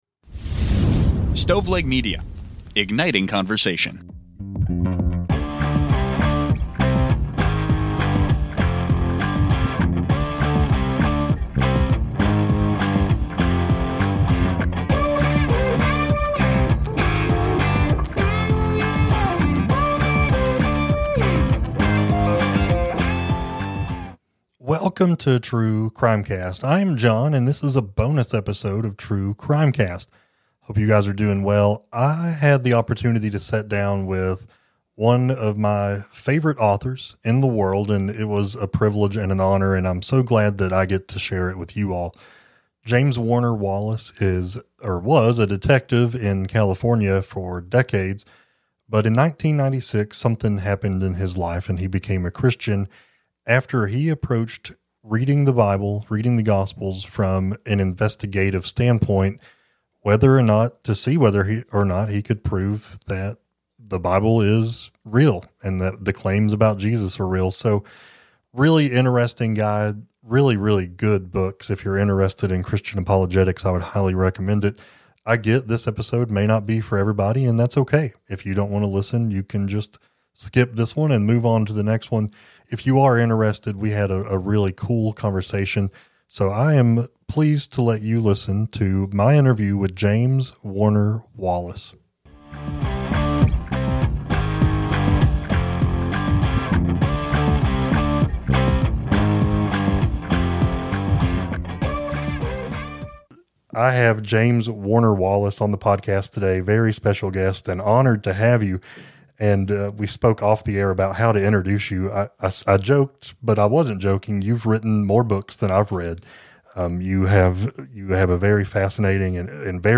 The Truth in True Crime - Interview with James Warner Wallace